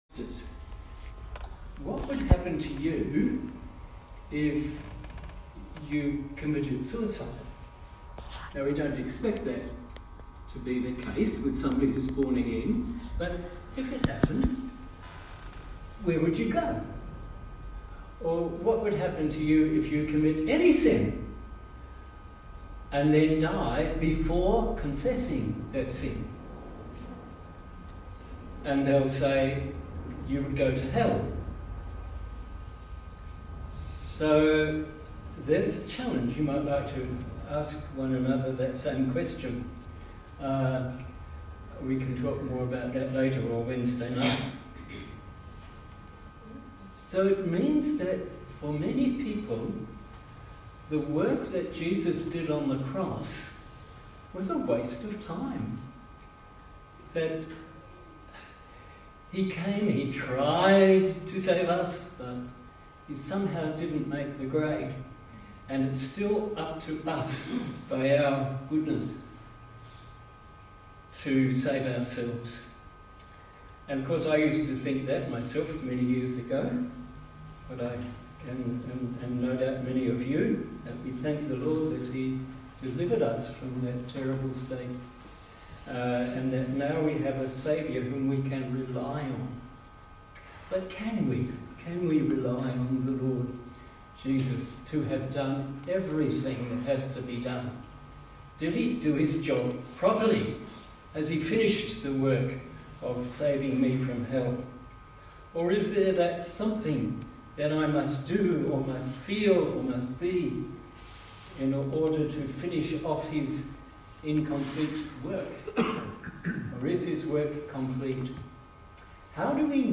Bible Text: 1 Cor 15:1-20 | Preacher
Easter Sunday